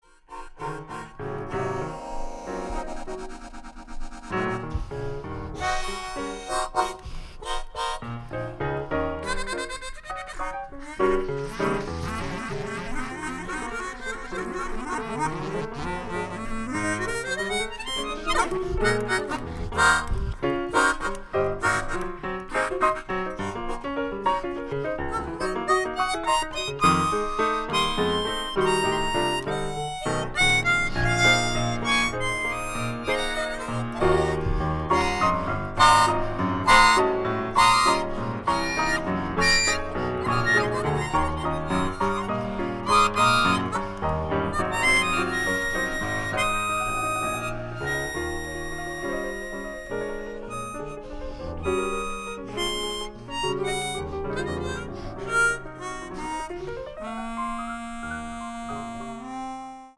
soul-jazz